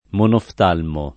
monoftalmo [ monoft # lmo ]